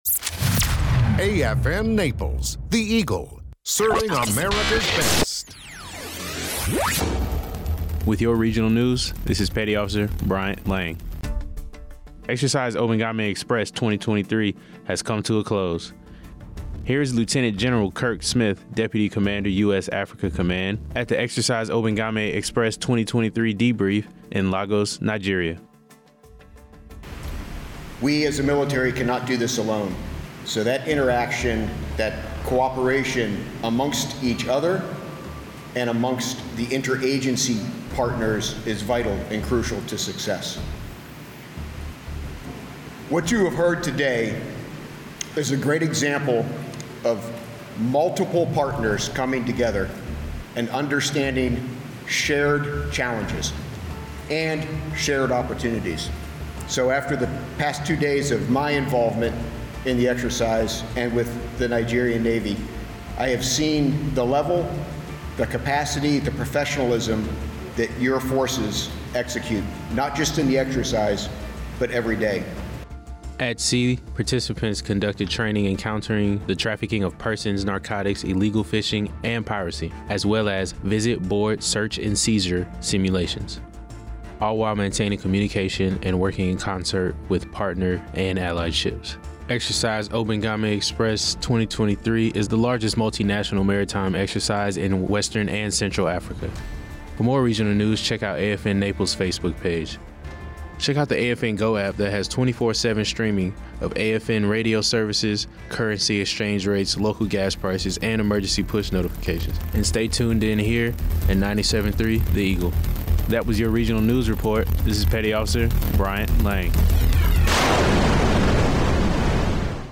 conclusionDebrief#Obangame